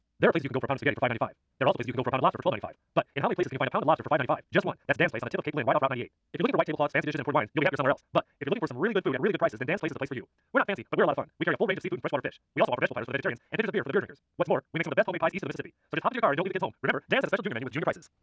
The audio examples here were compressed by Mach1 and by a linear technique, both to the same overall compression rate.
Monologs
The examples provided in this table are based on audio from the compact disks in the Kaplan TOEFL review materials.